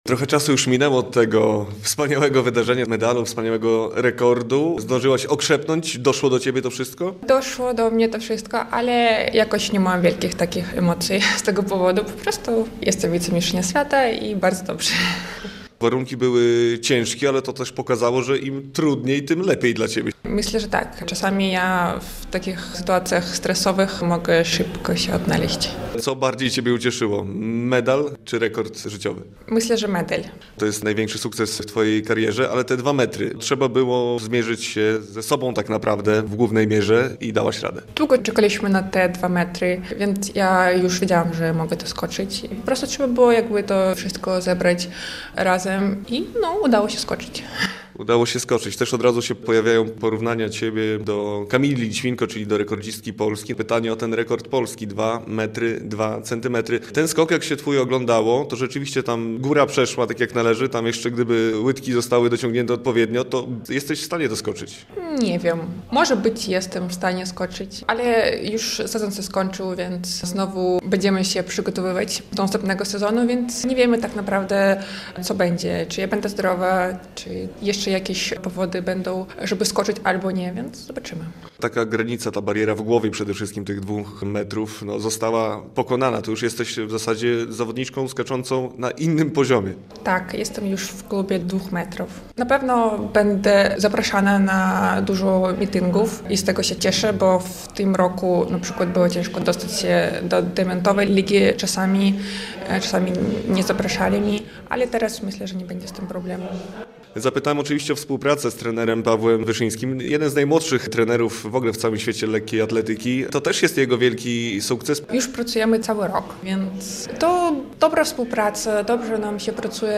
Radio Białystok | Gość | Maria Żodzik - wicemistrzyni świata w skoku wzwyż